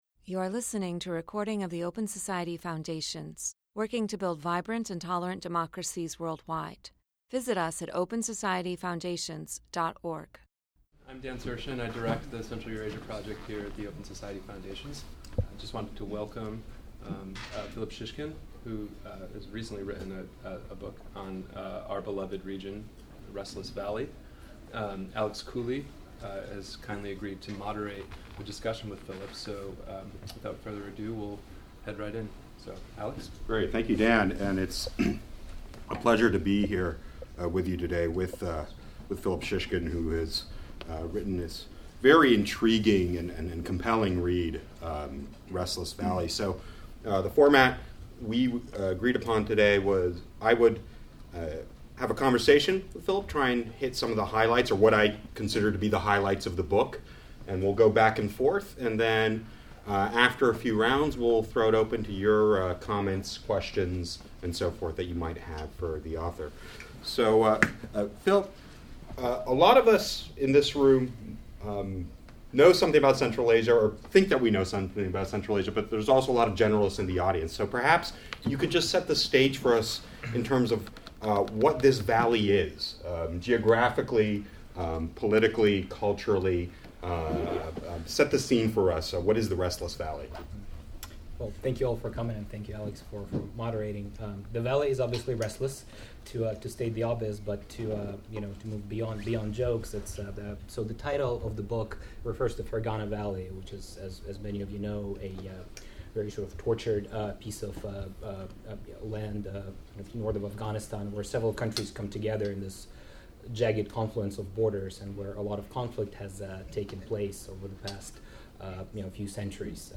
in conversation with political scientist